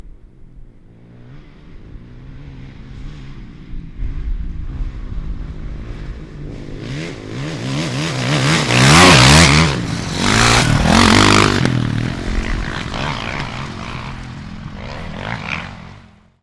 越野车 " 摩托车 越野车 越野车 接近爬坡 斗争，停止1
描述：摩托车越野车越野摩托车越野爬山，停止